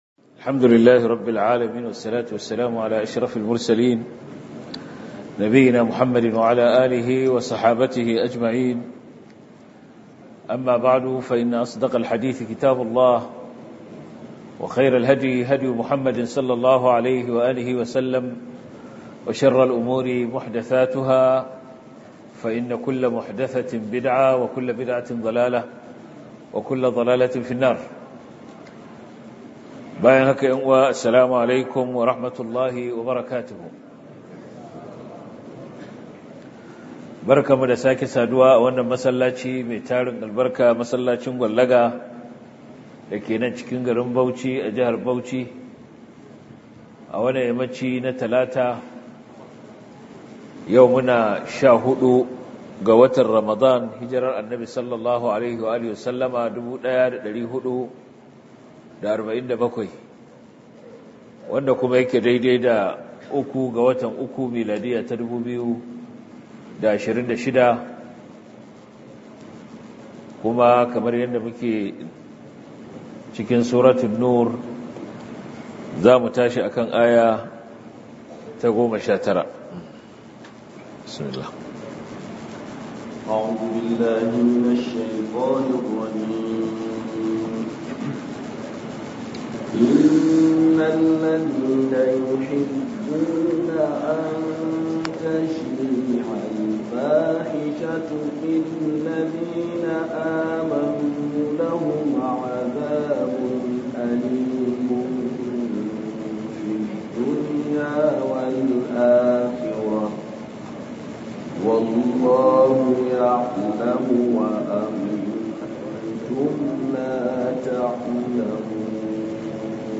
← Back to Audio Lectures 14 Ramadan Tafsir Copied!